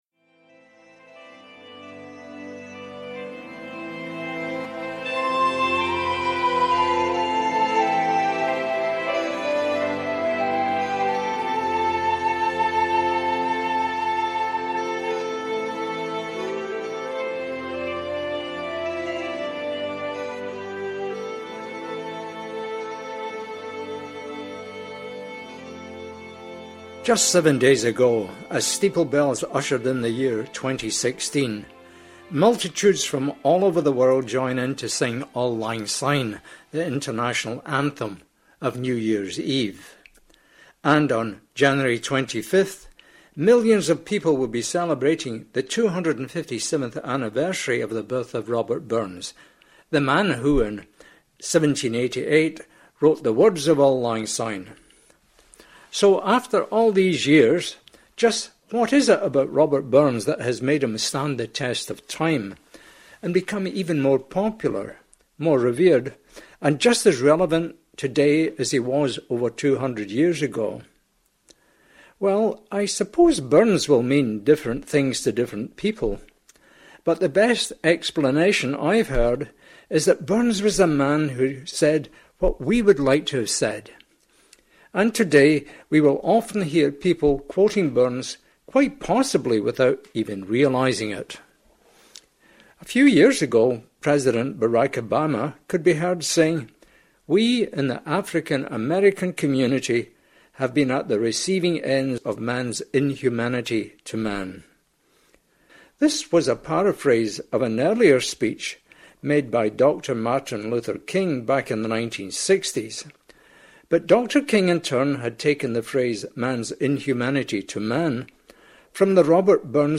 On Friday, January 8, our 2016 Burns celebrations got off to an early start with a special "Oor Club" lunch at the Duke of York pub in Toronto.
burns-talk-2016-inc-music.mp3